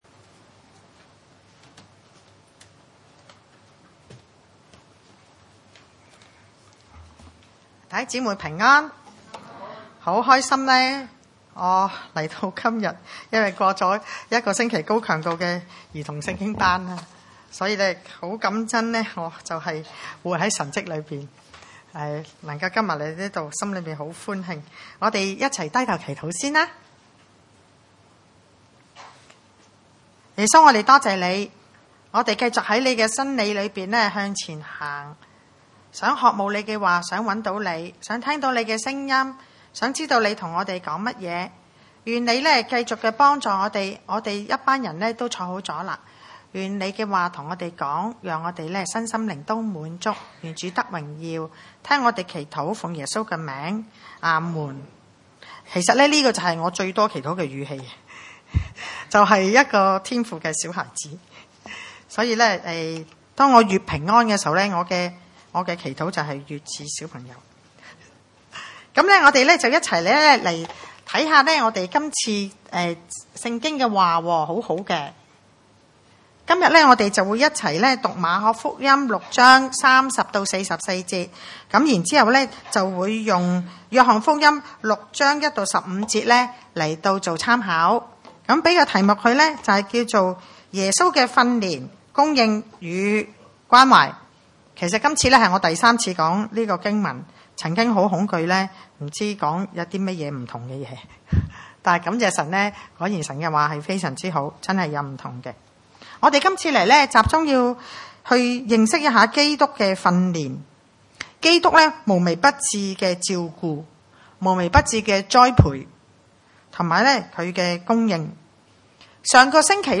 經文: 馬可福音 6:30-44 崇拜類別: 主日早堂崇拜 30 使徒聚集到耶穌那裡、將一切所作的事、所傳的道、全告訴他 。